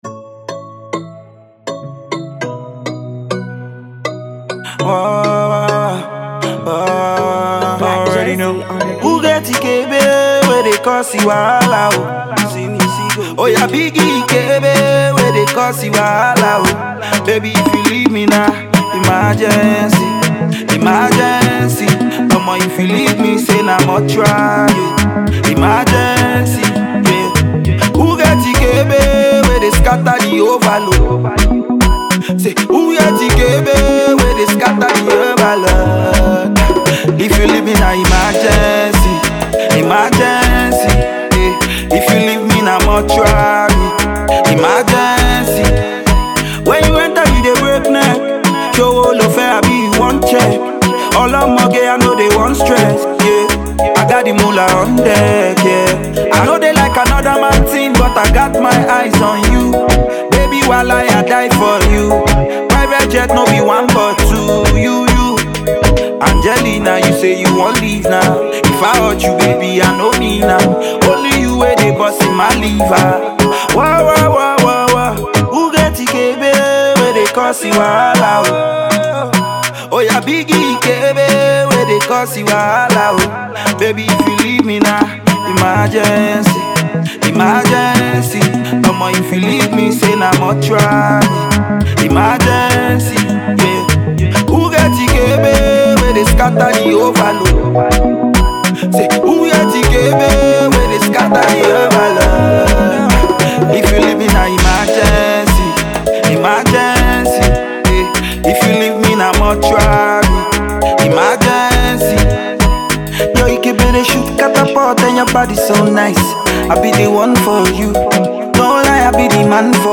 Afro-Pop songwriting-singing crooner